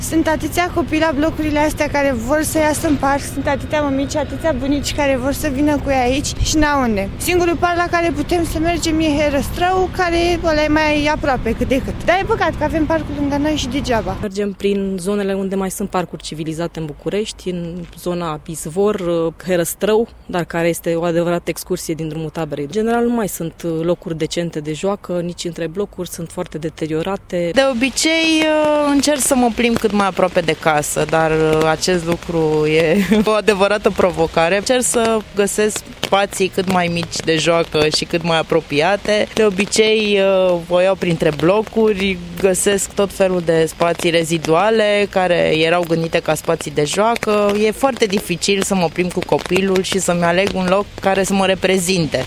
vox-mamici-8-apr-1.mp3